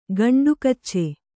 ♪ gaṇḍu kacce